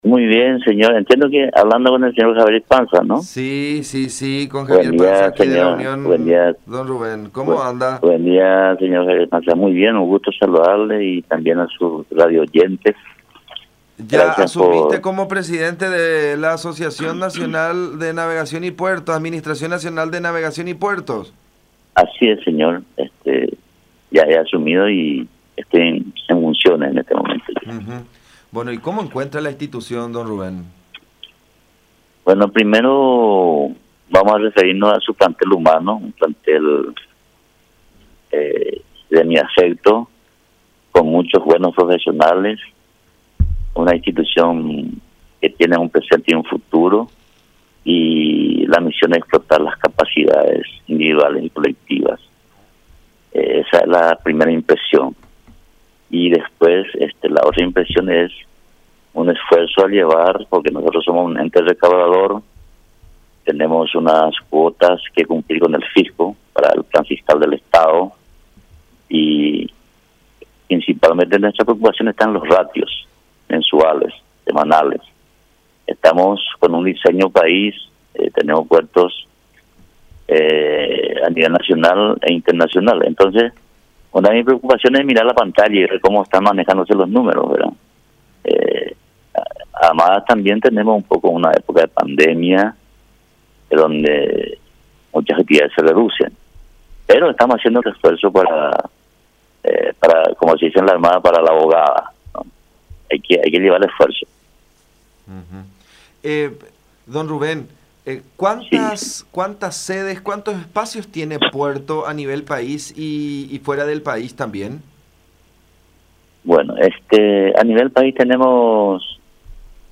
Tenemos que cumplir con el fisco, que nos exige recaudar G. 350 millones mensuales”, afirmó el nuevo presidente del ente en diálogo con La Unión